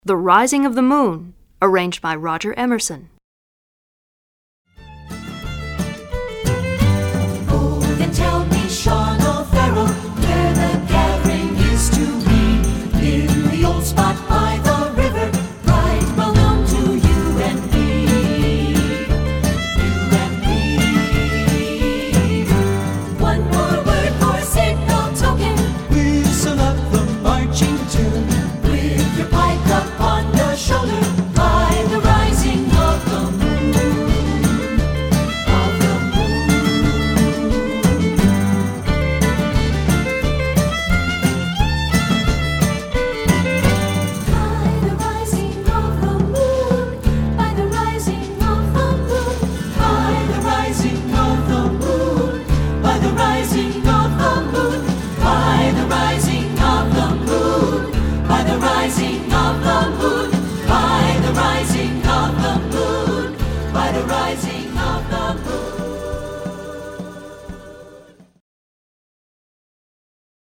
Composer: Traditional Irish
Voicing: SATB